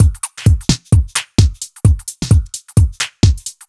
Index of /musicradar/uk-garage-samples/130bpm Lines n Loops/Beats
GA_BeatnPercE130-01.wav